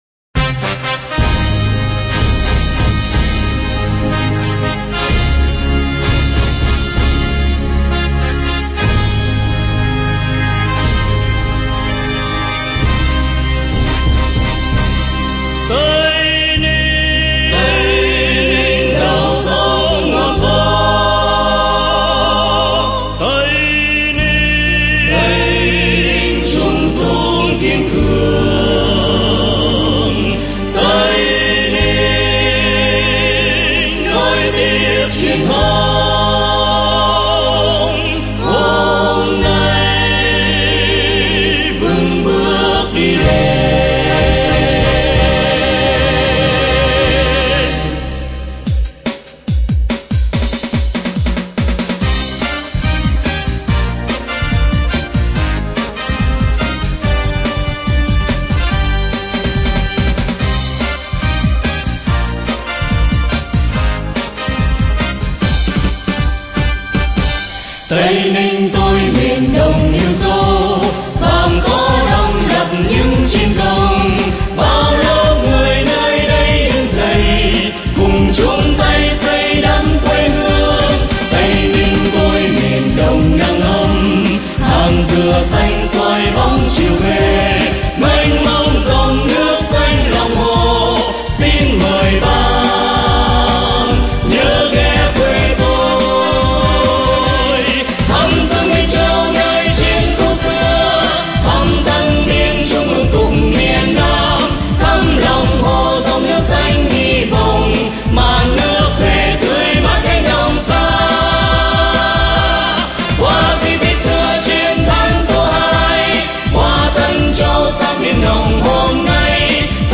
thuộc thể loại Dân ca trữ tình quê hương.